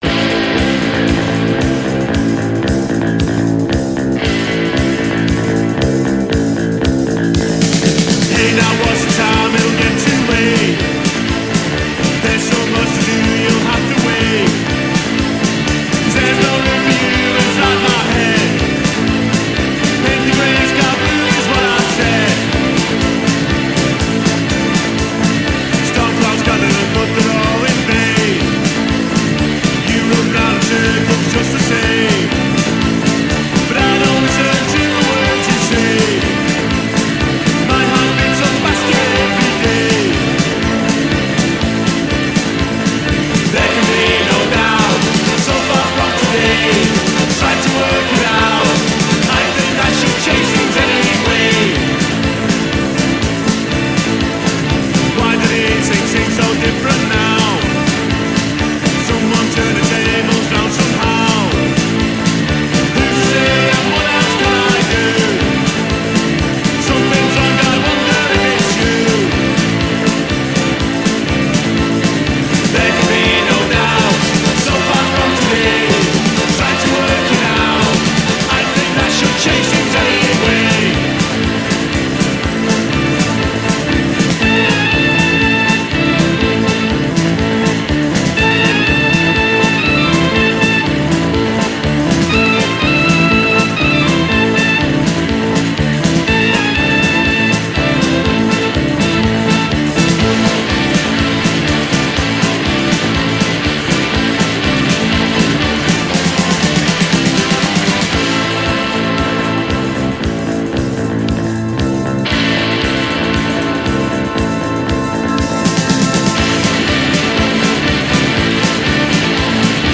Peel Session – first broadcast 1st August 1988